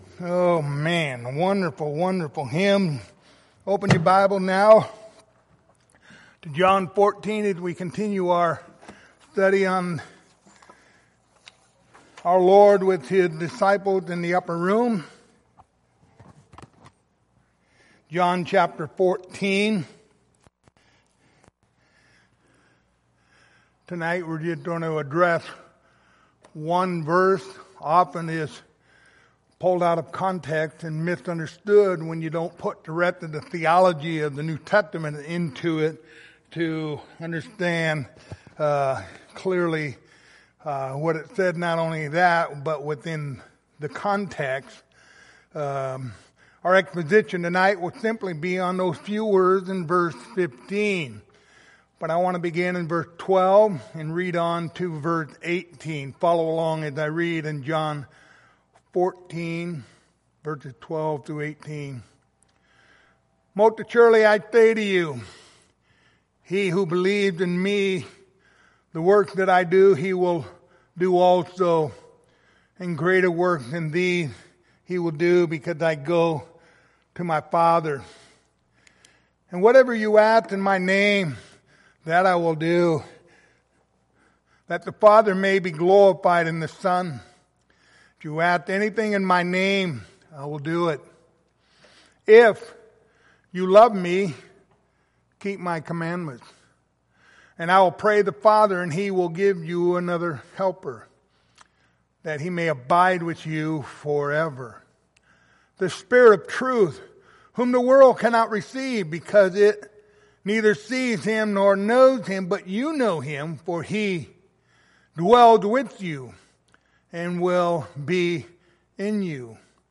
Passage: John 14:15 Service Type: Wednesday Evening